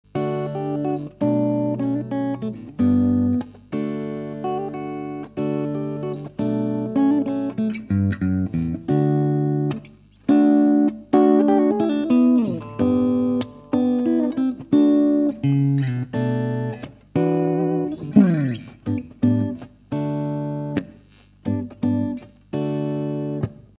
一応、普通のストラトキャスターから直結した
音をアップしておきます。もちろんフルヴォリュームです。